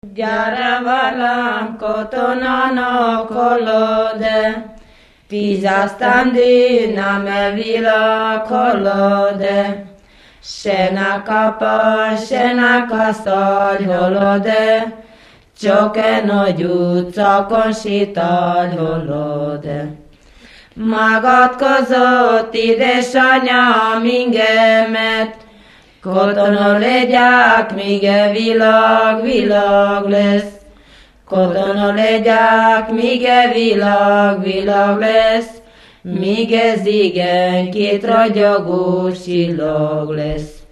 Moldva és Bukovina - Moldva - Külsőrekecsin
Műfaj: Katonadal
Stílus: 4. Sirató stílusú dallamok
Kadencia: 5 (4) 5 1